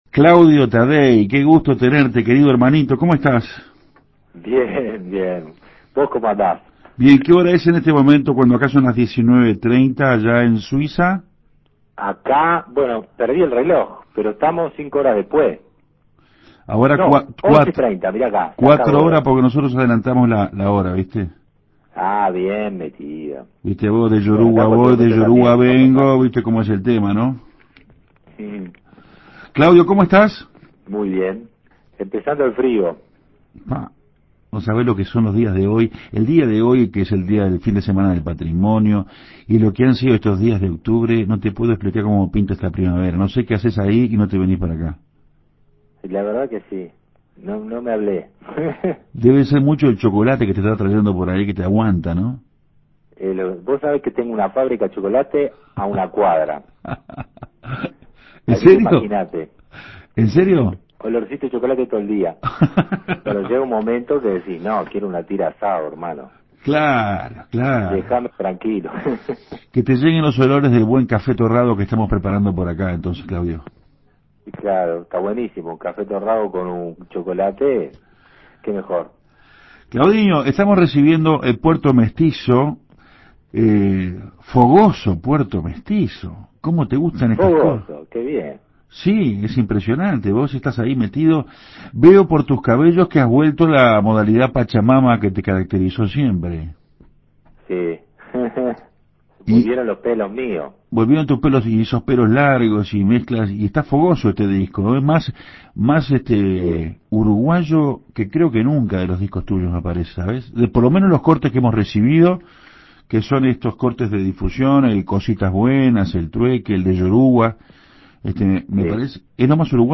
Claudio Taddei, vía telefónica desde Suiza, nos contó sobre su nuevo trabajo "Puerto Mestizo".